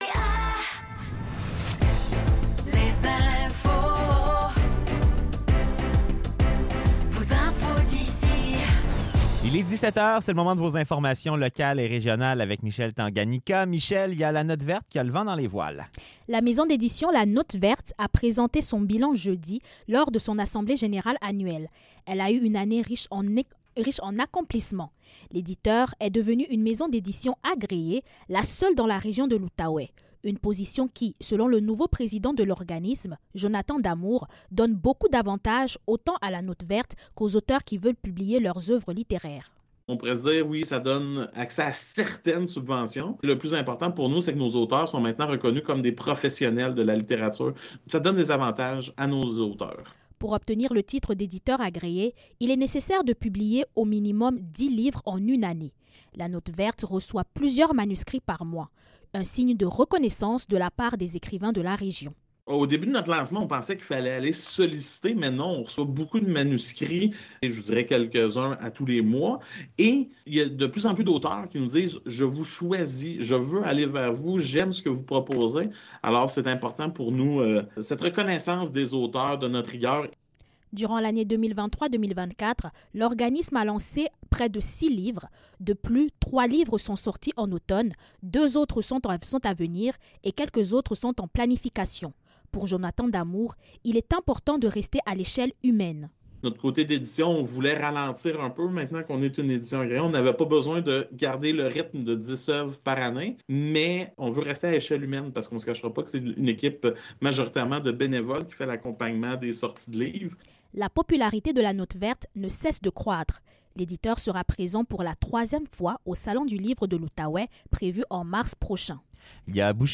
Nouvelles locales - 11 décembre 2024 - 17 h